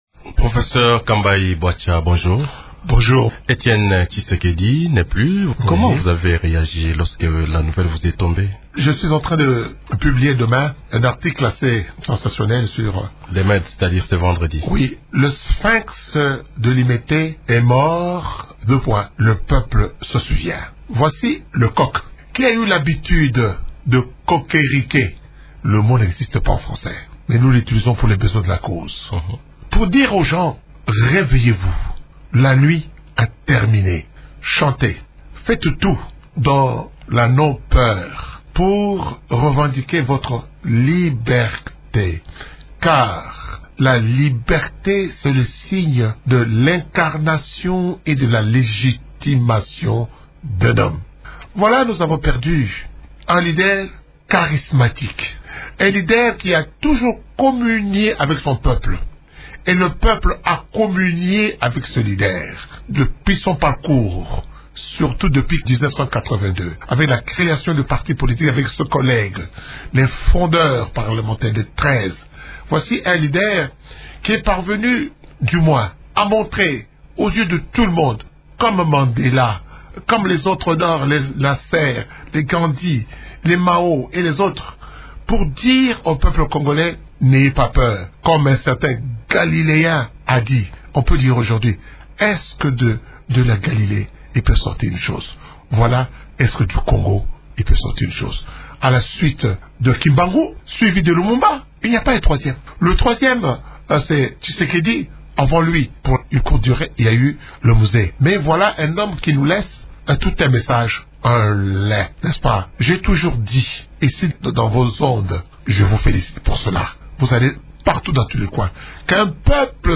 s’entretient